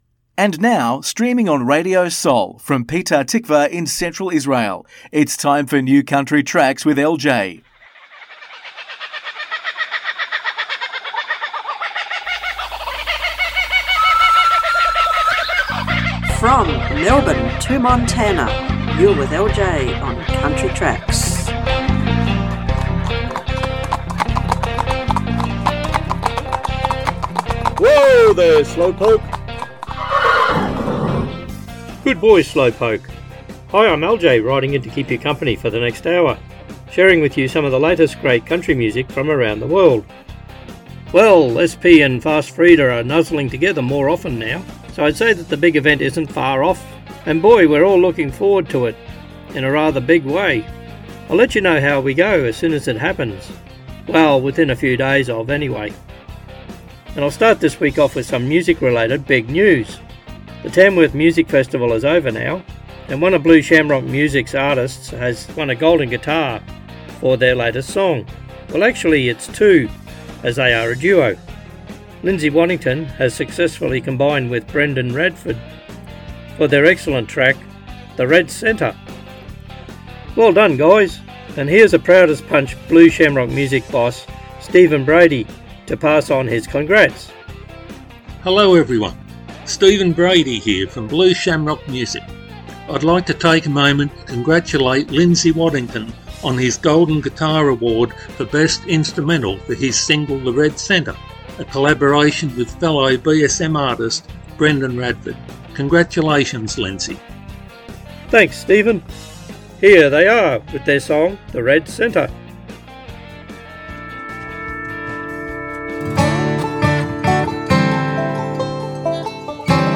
מוזיקת קאנטרי ואינדי עולמית - התכנית המלאה 14.2.25